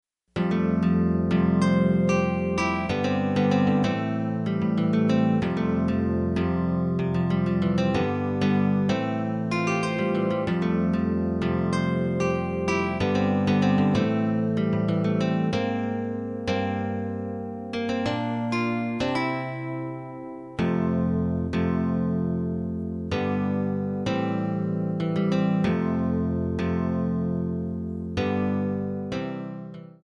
Backing track files: Pop (6706)
Buy Without Backing Vocals